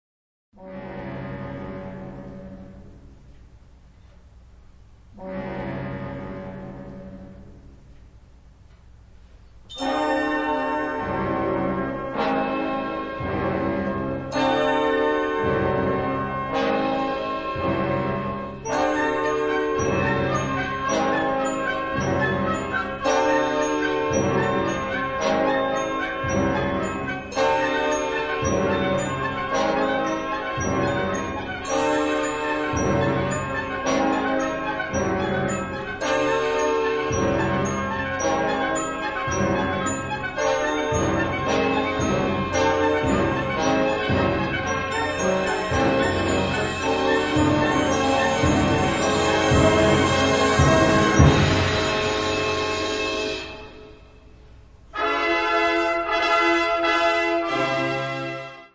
Gattung: Konzertante Blasmusik
Besetzung: Blasorchester
Dieses kraftvolle Stück